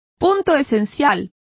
Complete with pronunciation of the translation of crux.